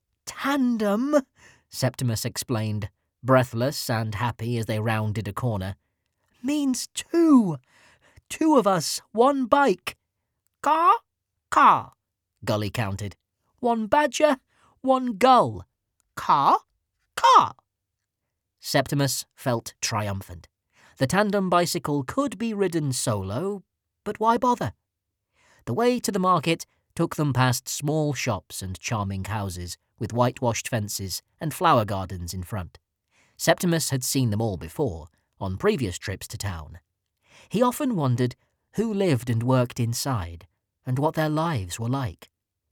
Narrator
Bad Badger (Earphones Winner - Childrens Fiction).mp3